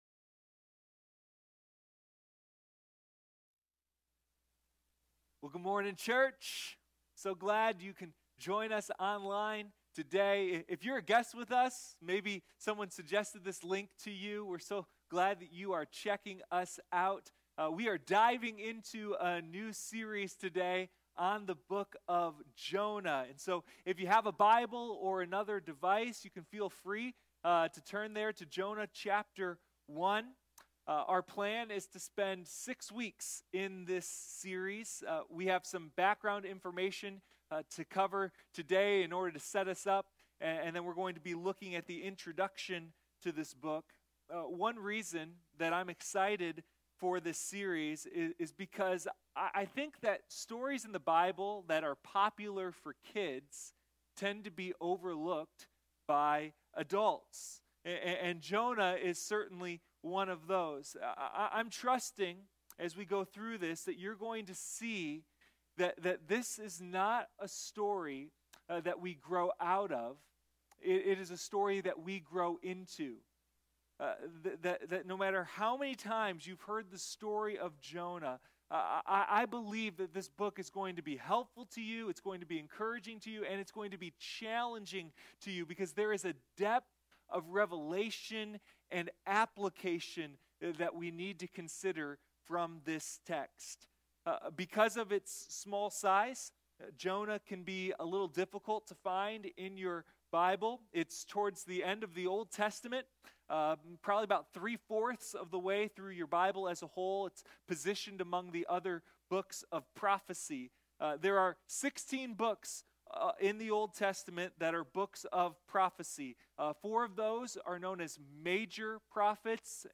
Sunday Morning Jonah: a deep dive into God's mercy